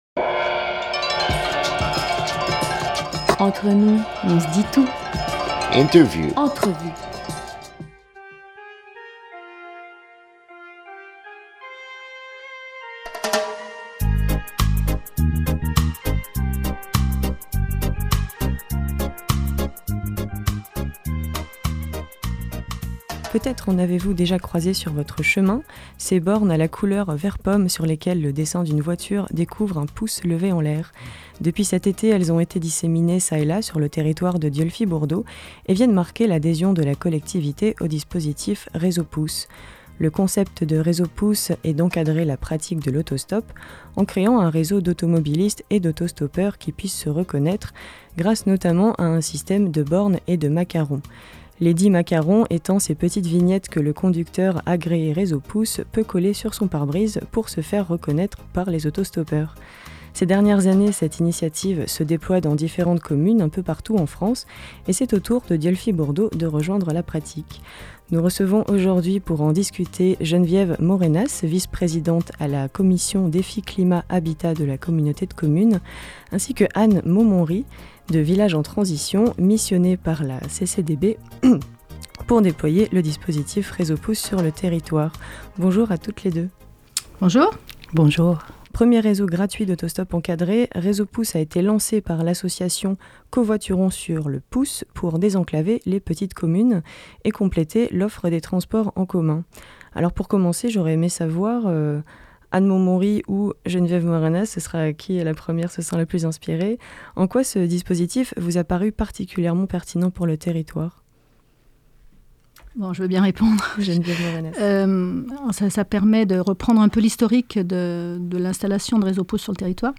27 septembre 2021 8:00 | Interview